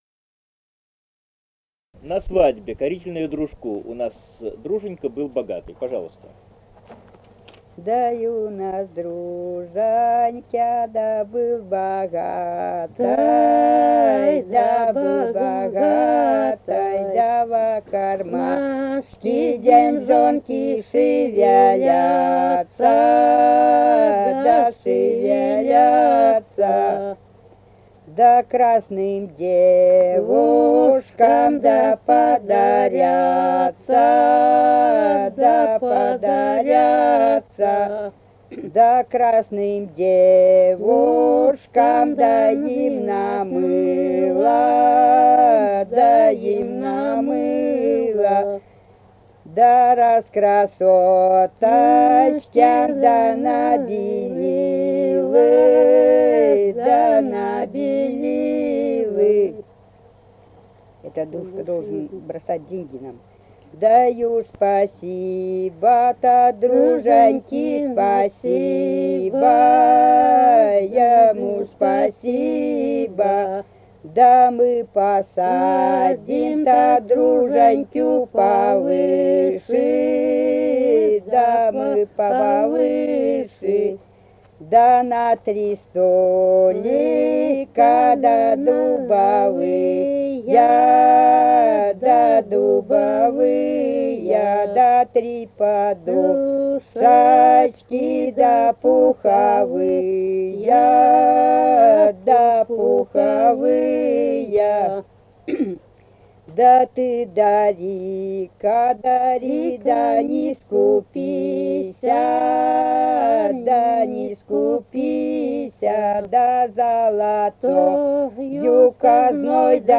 Республика Казахстан, Восточно-Казахстанская обл., Катон-Карагайский р-н, с. Белое / с. Печи.